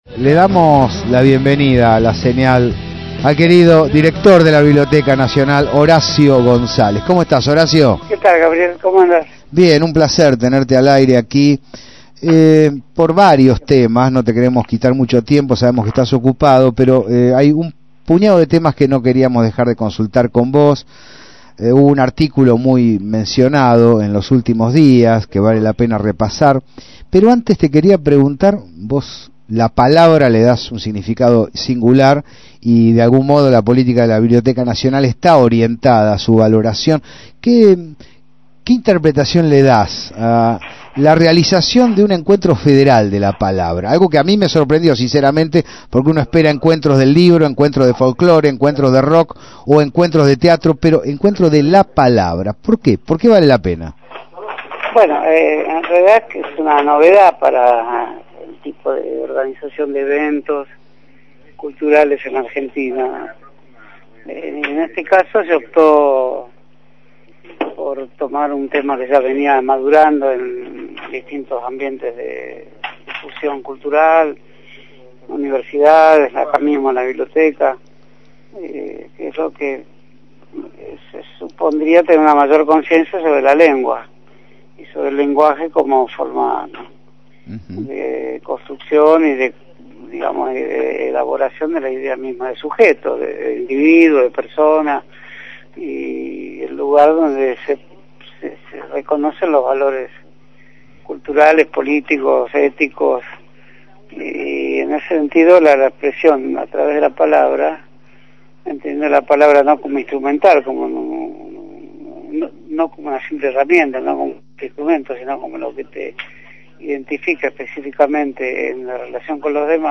Horacio González, sociólogo, ensayista y director de la Biblioteca Nacional, fue entrevistado en La Señal. En el marco del Encuentro Federal de la Palabra que se está llevando a cabo en Tecnópolis, analizó el uso de la misma en los distintos contextos.